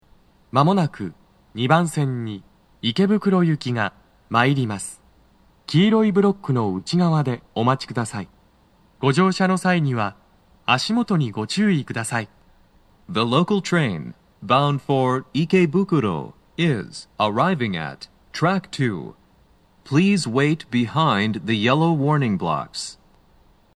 スピーカー種類 TOA天井型()
鳴動は、やや遅めです。
2番線 中野坂上・池袋方面 接近放送 【男声